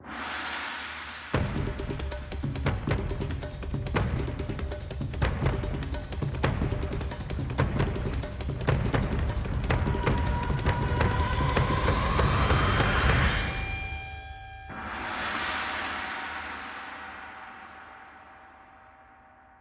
A battle theme
Ripped from the game
clipped to 30 seconds and applied fade-out